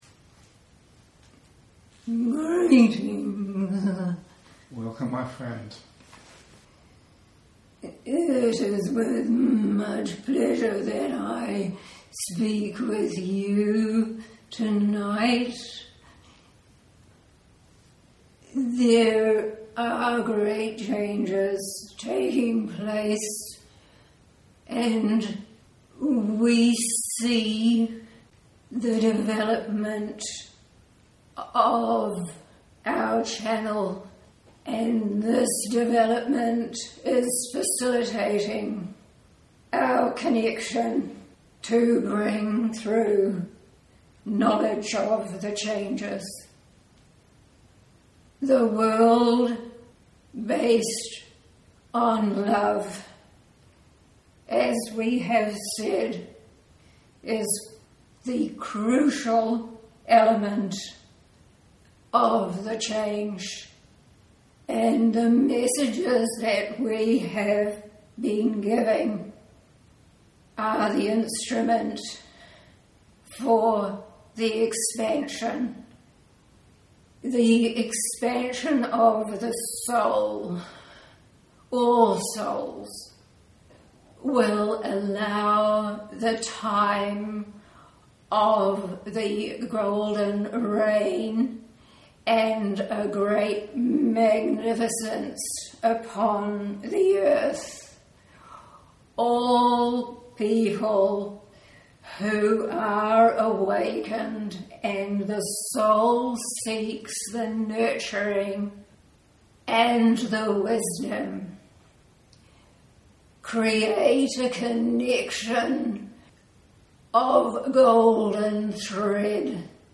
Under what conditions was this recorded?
Bear in mind that it is a live recording and please excuse the deficiencies in sound quality.